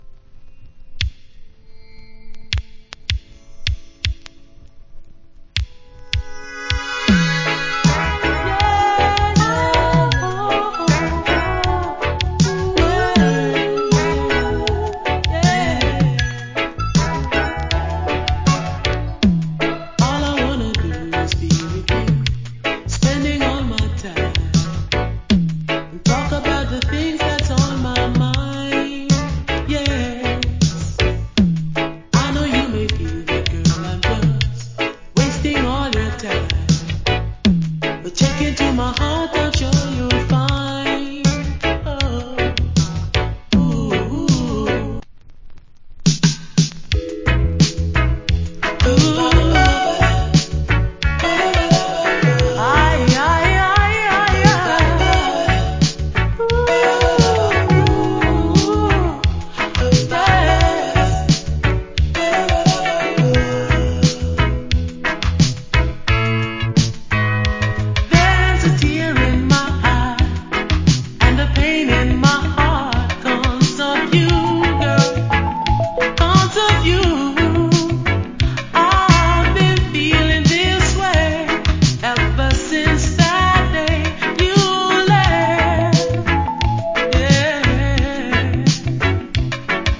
80's. Cool Female UK Lovers.